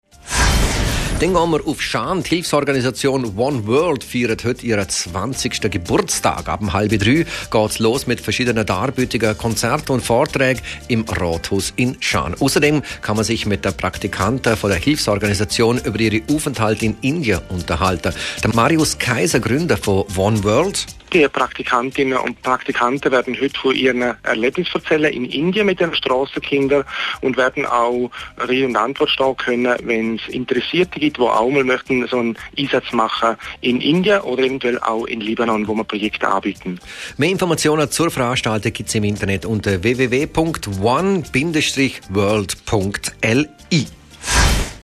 veranstaltungskalender-radio-l.mp3